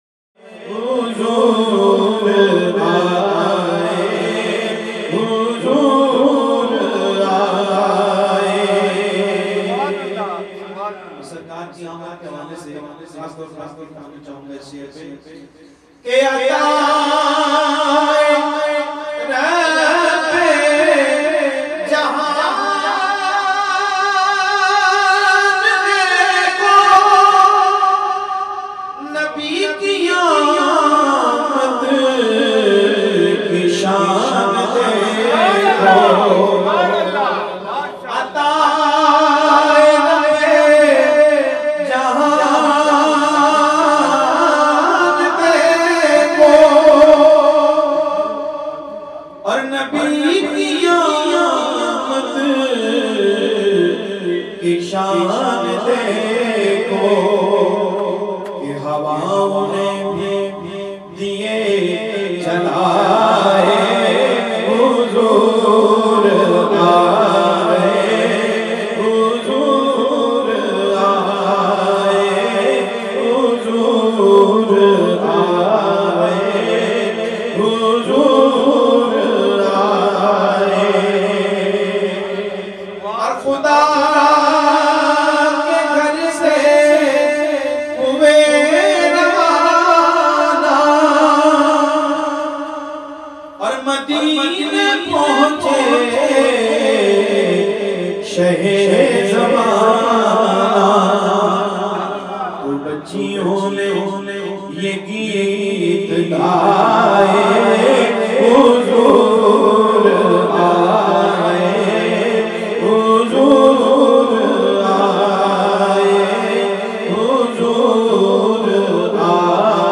very sweet and magical voice with wonderful control